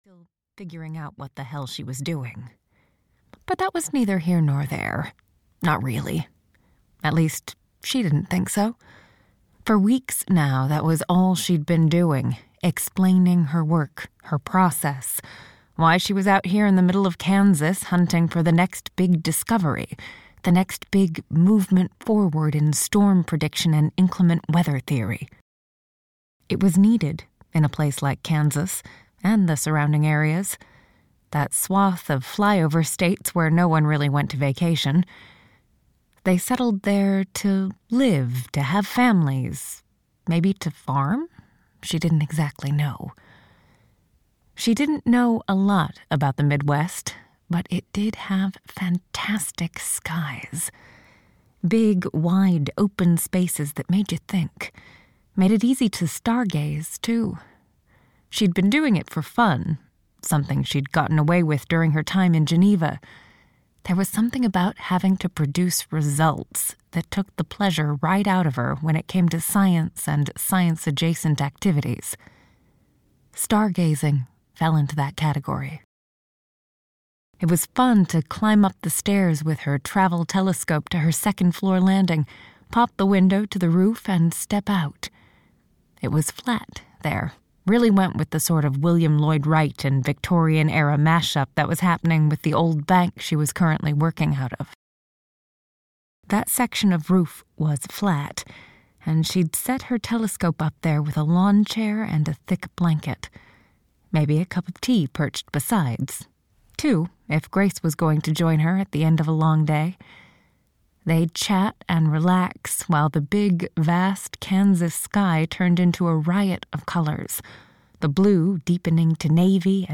Love and Thunder (EN) audiokniha
Ukázka z knihy